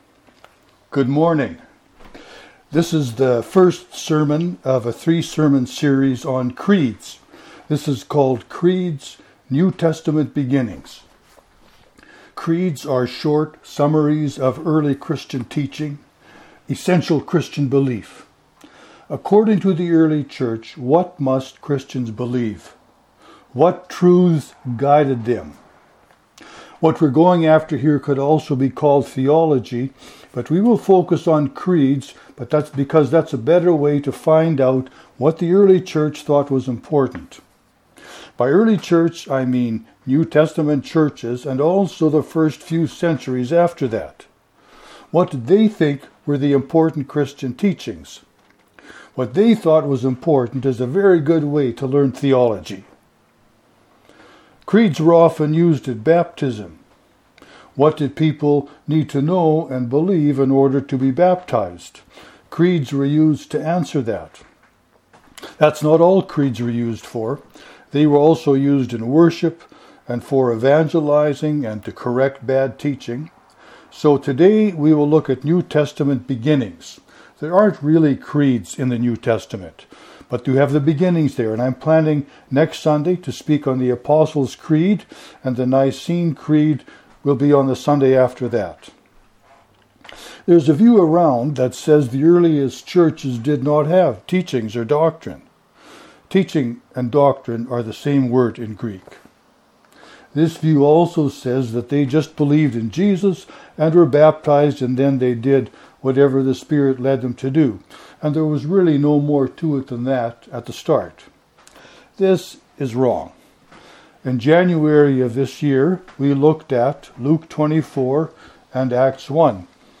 This is the first sermon of a three-sermon series on “creeds.” Creeds are short summaries of early Christian teaching, of essential Christian belief.